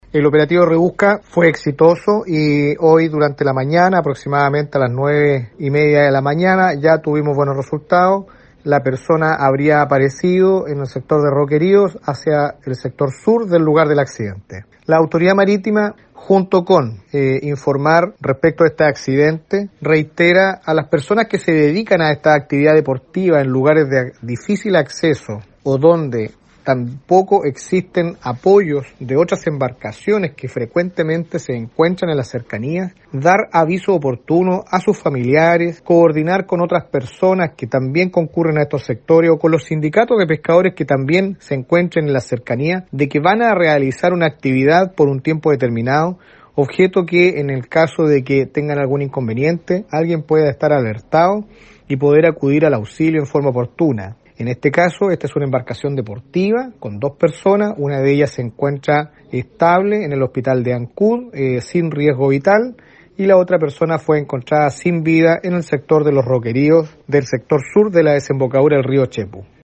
El gobernador marítimo de Castro, Héctor Aravena dio a conocer todo el procedimiento de rigor que se adoptó una vez conocida la noticia.
CUÑA-2-HECTOR-ARAVENA-1.mp3